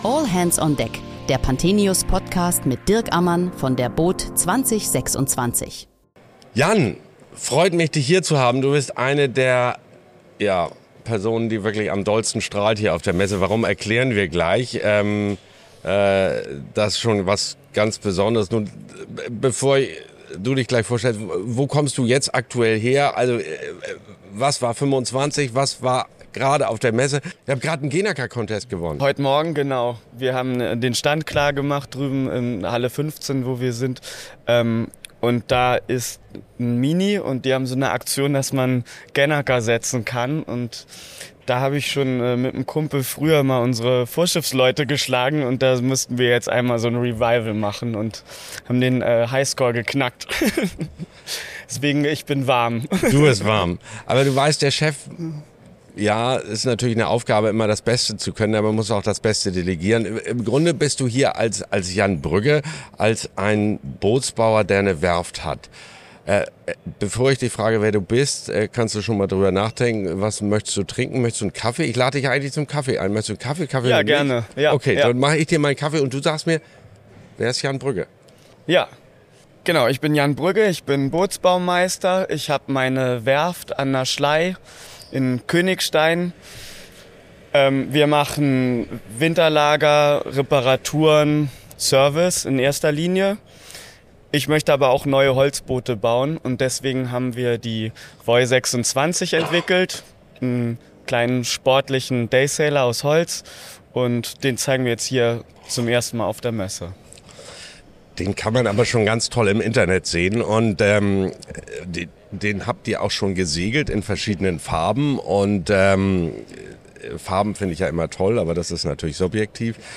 Während der boot Düsseldorf 2026 sprechen wir täglich mit Gästen aus der Branche über aktuelle und kontroverse Themen rund um den Wassersport.